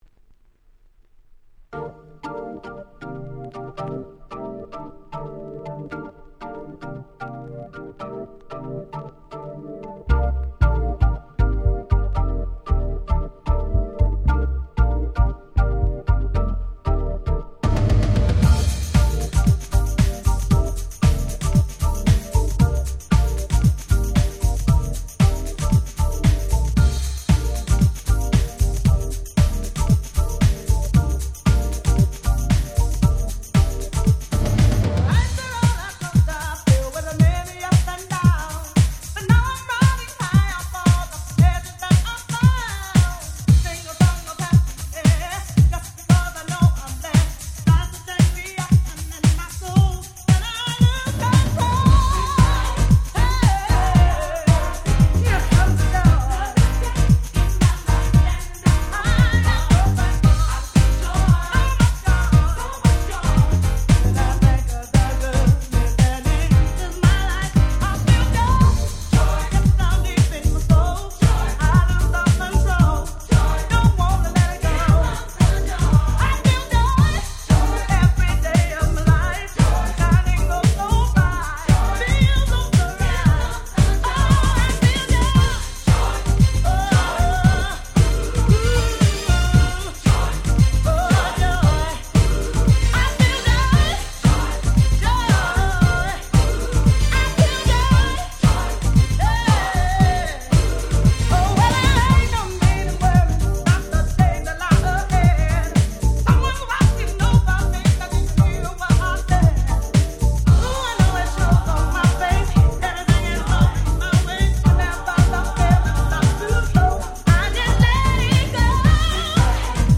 92' Very Nice Christmas Song / R&B !!
Gospel